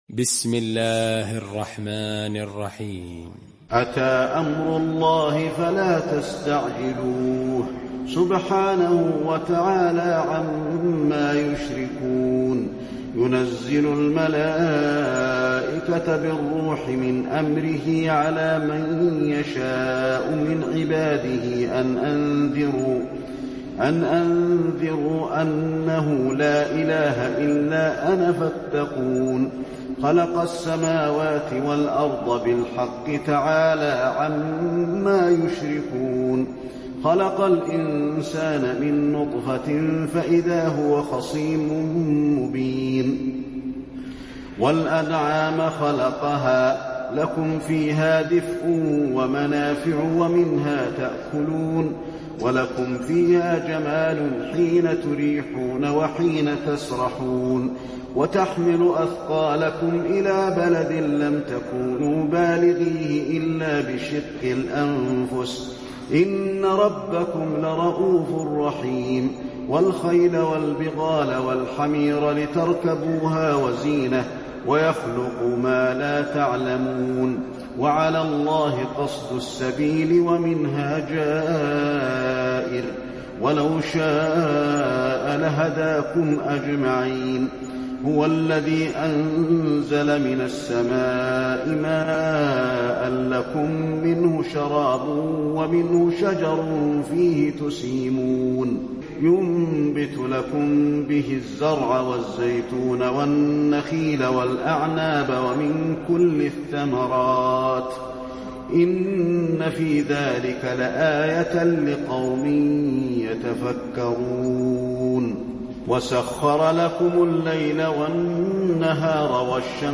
المكان: المسجد النبوي النحل